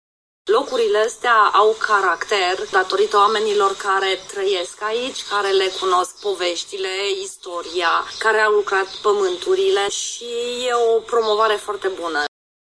Proprietar casă de oaspeți